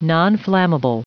Prononciation du mot nonflammable en anglais (fichier audio)
Prononciation du mot : nonflammable